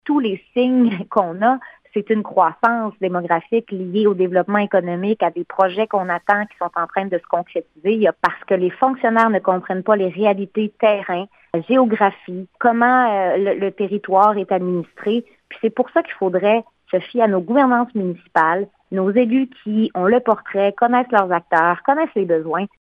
Méganne Perry Melançon avoue que le dossier est un autre exemple où les fonctionnaires ne comprennent pas les réalités régionales et que le ministère de la Famille devrait davantage faire confiance aux organismes et aux élus de la région :